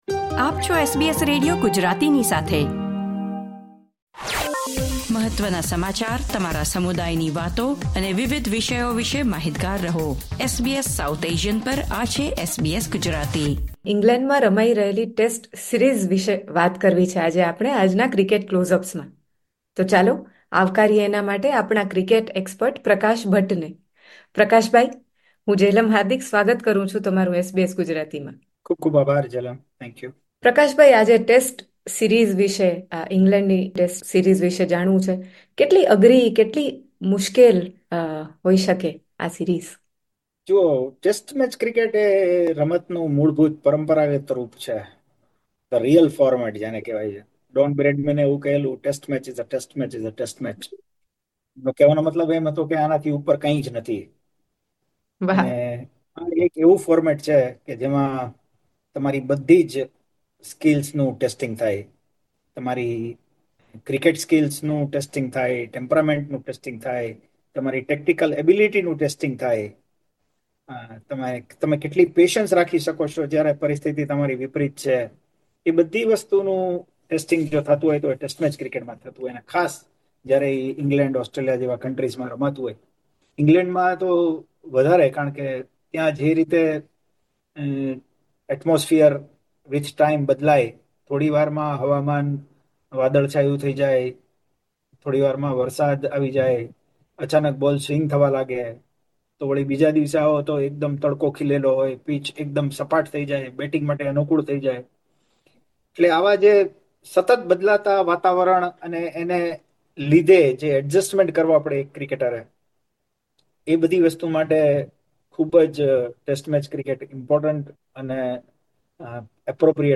વાત કરી રહ્યા છે ક્રિકેટ નિષ્ણાત